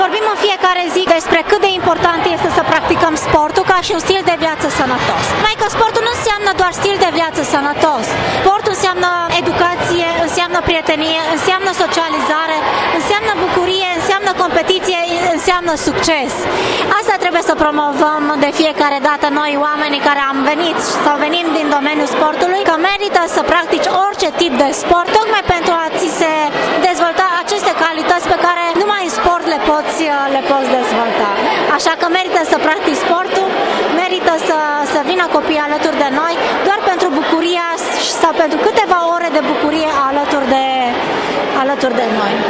Ministrul tineretului si sportului Gabriela Szabo spune ca sportul nu inseamna numai un stil de viata sanatos.